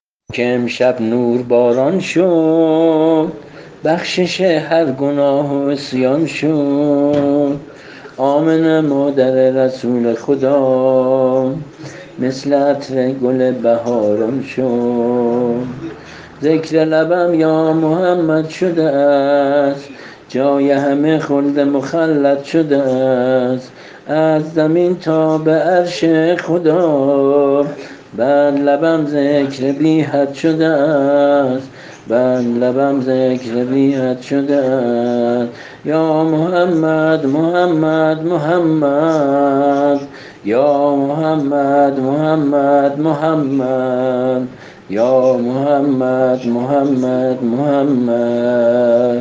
سرود هفته‌ی وحدت